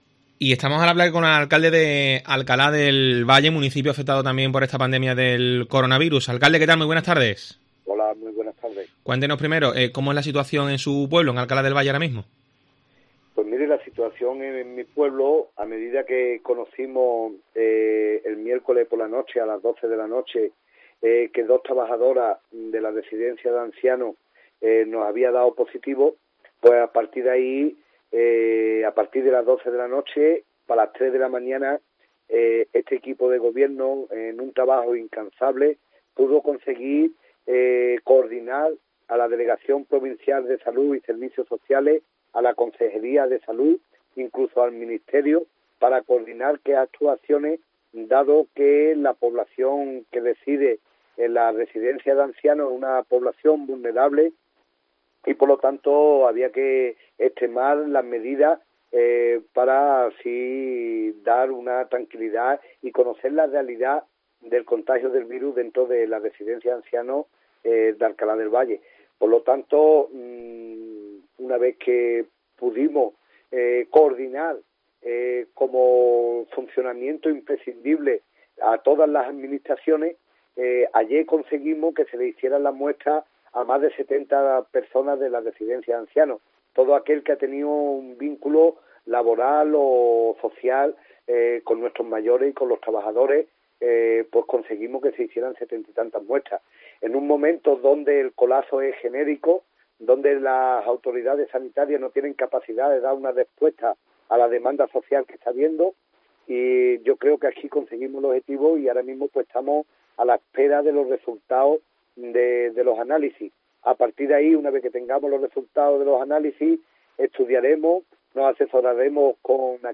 Rafael Aguilera, alcalde de Alcalá del Valle, explica el impacto del coronavirus en el municipio